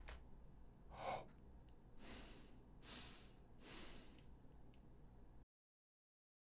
【歌唱スキル03】ヒューマンビートボックス①
まず、口の中に空気をためて口を膨らませた状態で、鼻で呼吸をします。（フグのように
※見本音声
h05_HBB_clap_hanakokyu.mp3